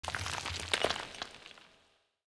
踩死虫子－YS070511.wav
通用动作/01人物/01移动状态/踩死虫子－YS070511.wav
• 声道 單聲道 (1ch)